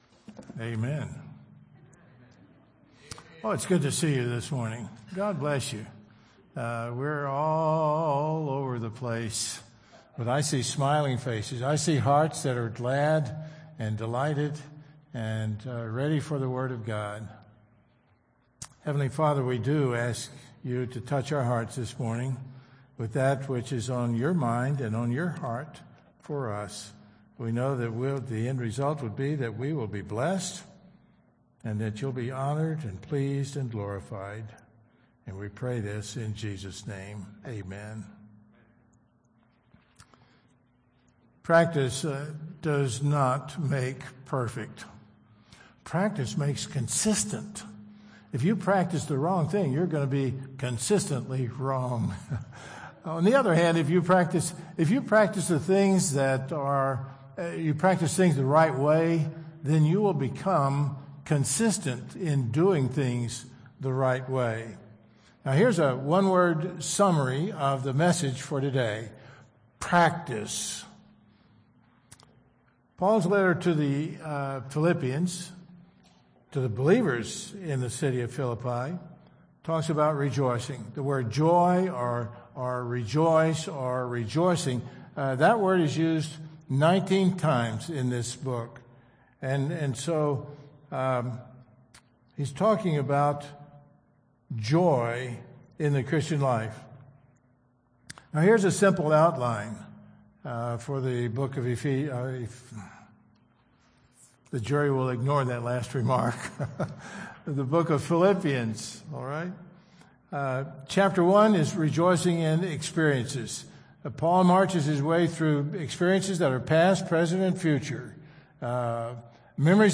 Worship Service October 31, 2021